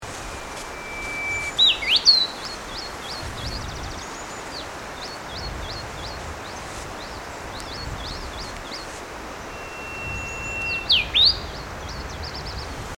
With my LS-11 Olympus voice recorder I made the following recordings of bird songs, all in stereo: